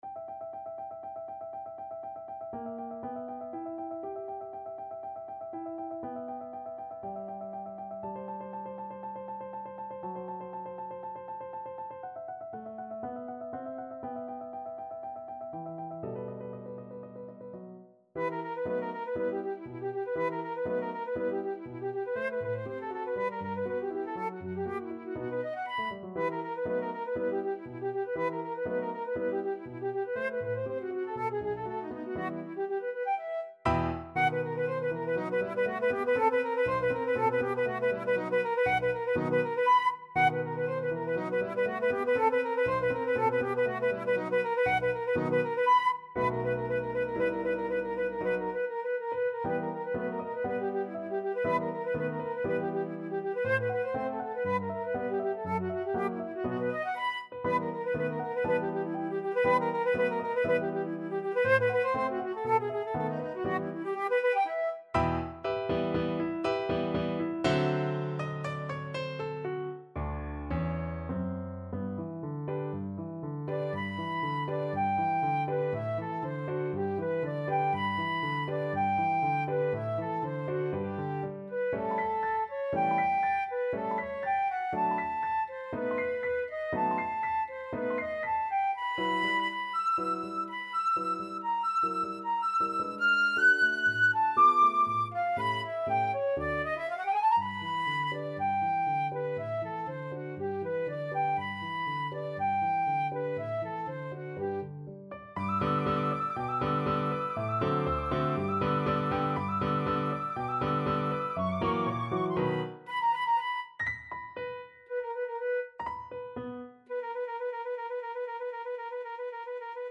2/4 (View more 2/4 Music)
= 120 Allegro molto vivace (View more music marked Allegro)
Classical (View more Classical Flute Music)